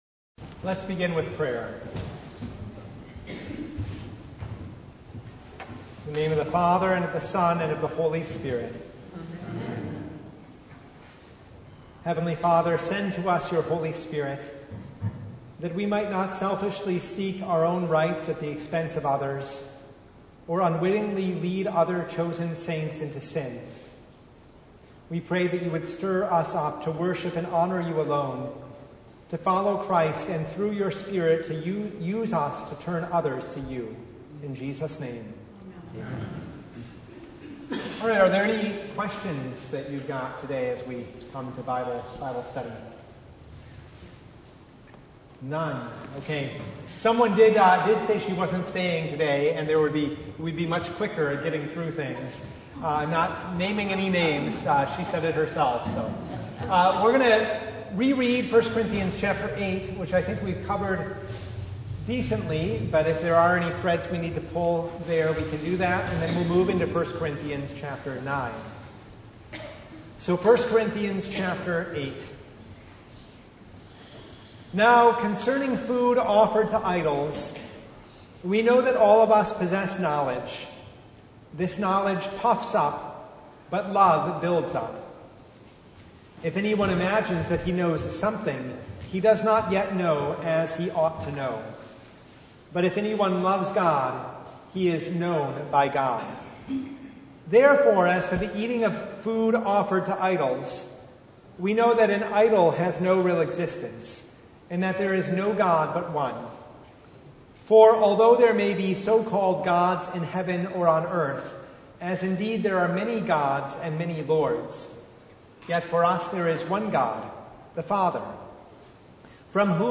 1 Corinthians 9:1-27 Service Type: Bible Hour Topics: Bible Study « The Plentiful Harvest The Third Sunday in Apostles’ Tide